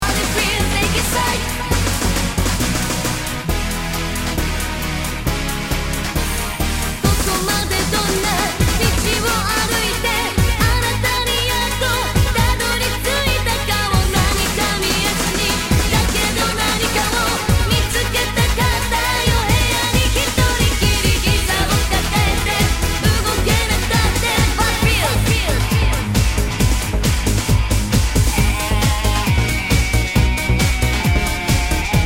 中古なので、スレや傷がありますがノイズはありません。
Category       レコード / vinyl 12inch
Tag       Japan R&B